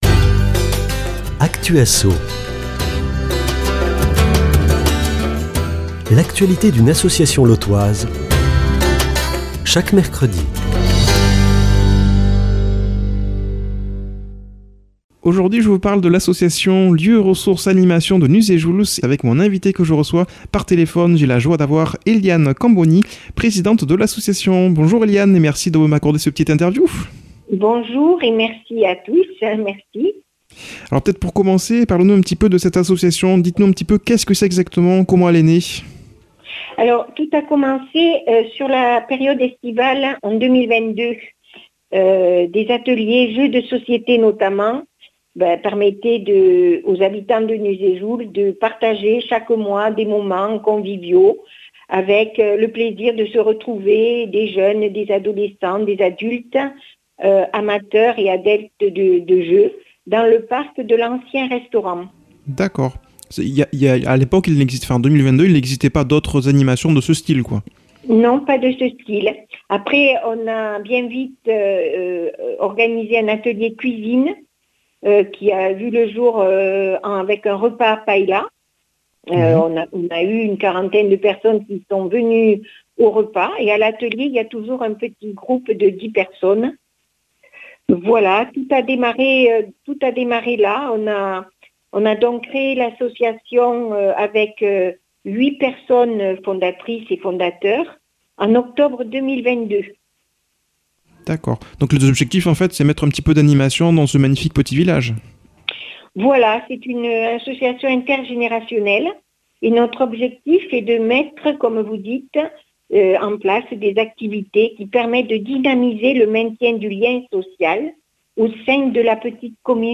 invitée par téléphone